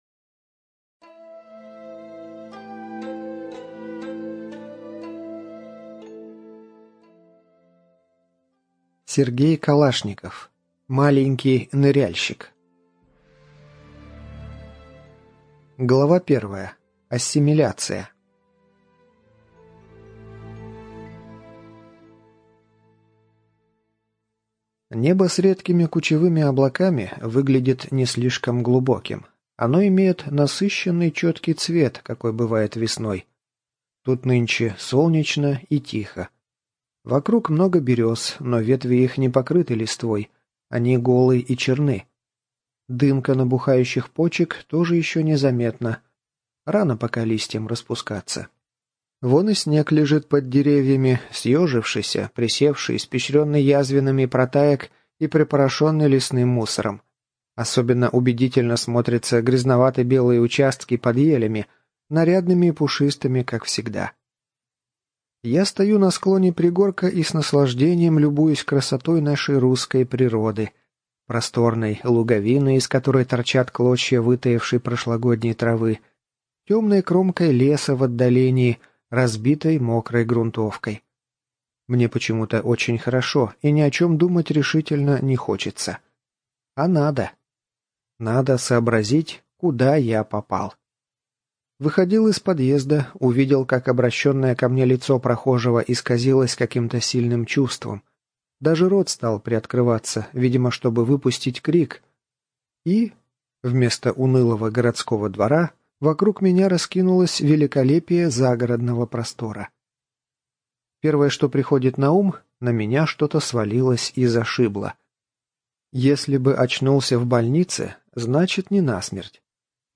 Жанр: Военная фантастика